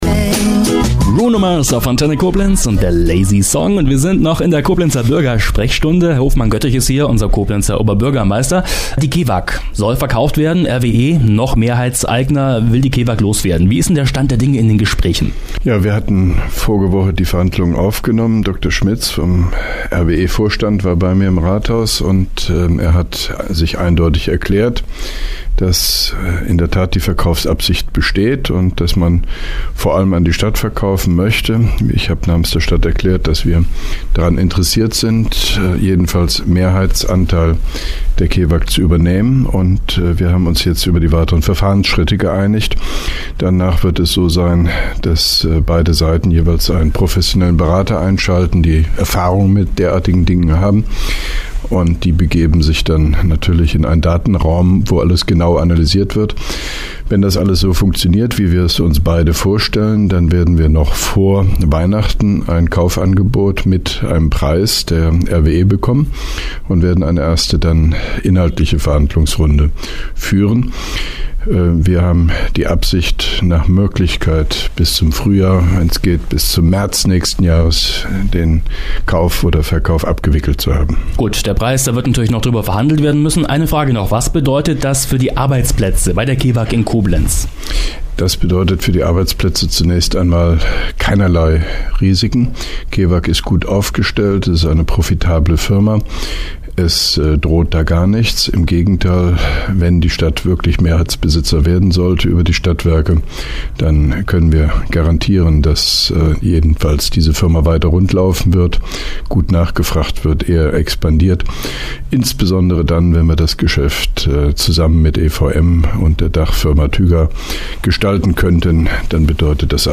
Antenne Koblenz 98,0 am 18.10.2011, ca. 8.55 Uhr (Dauer 02:12 Minuten)